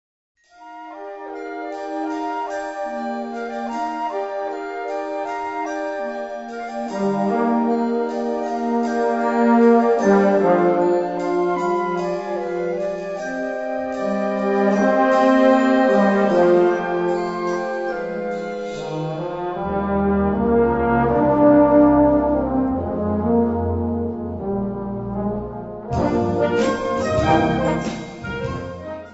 Featuring easy style changes..
Besetzung: Blasorchester
jazzy